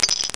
COINDROP.mp3